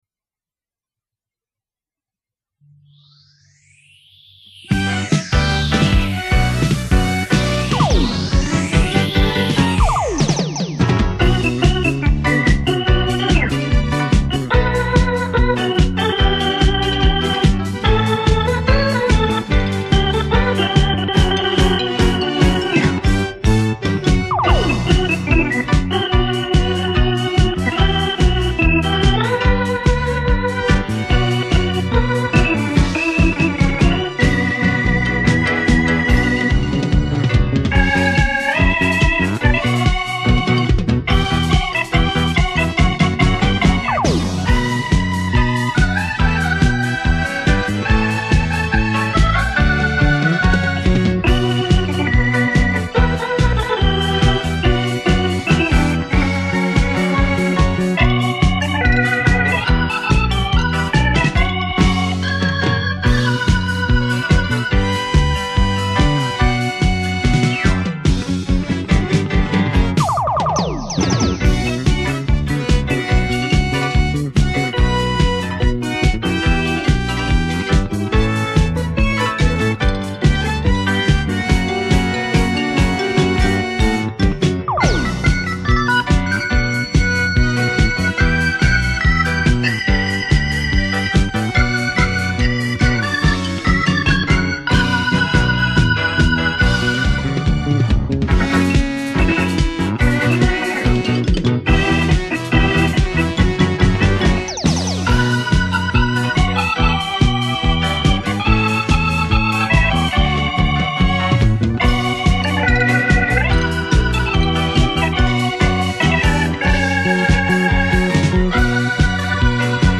当年流行金曲，朴实的配器，熟悉的旋律，一份青春年少的回忆，往事值得在音乐中回味，记忆永久珍藏